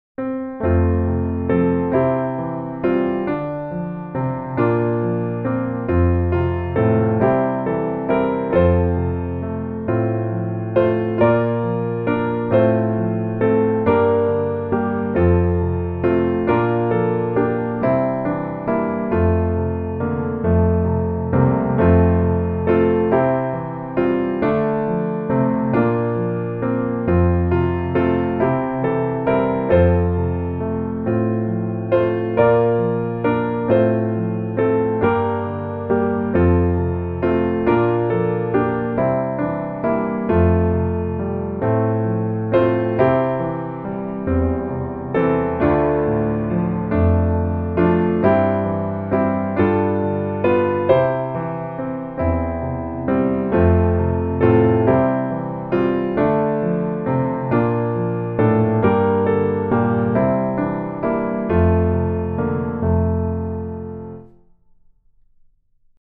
F Major